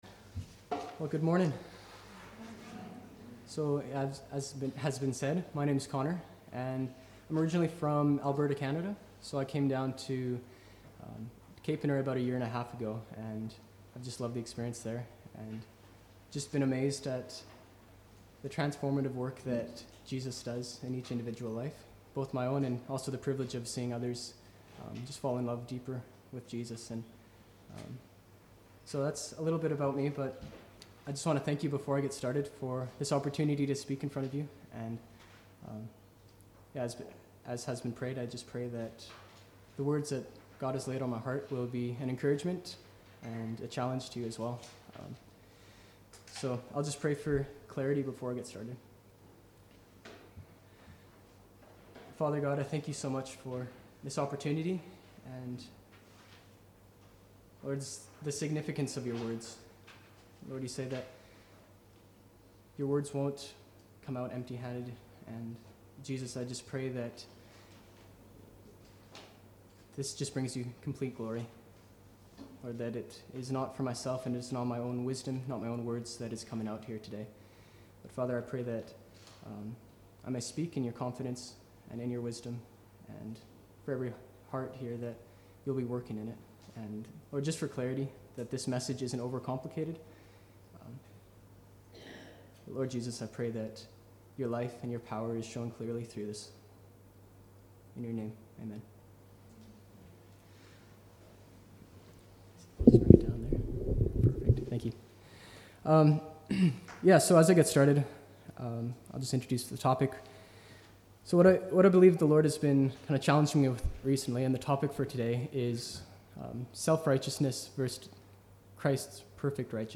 22.7.18-Sunday-Service-Self-righteousness-versus-Christs-righteousness.mp3